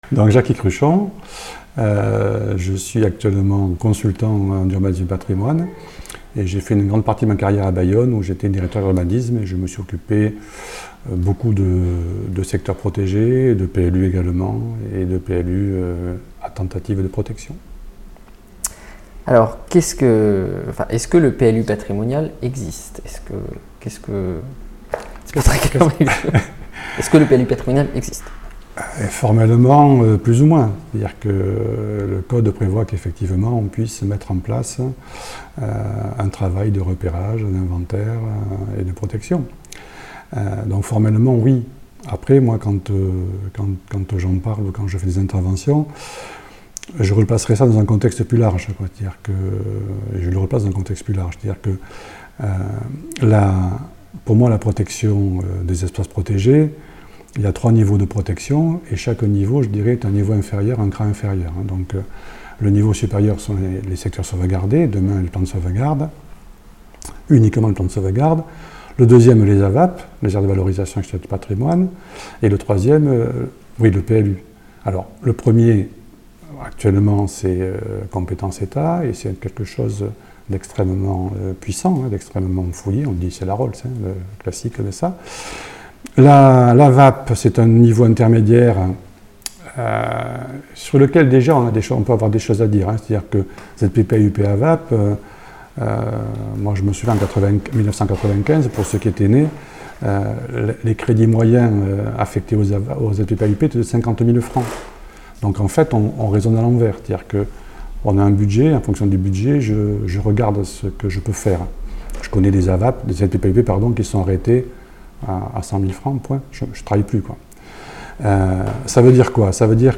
Entretien réalisé à Bordeaux le 23 mai 2016.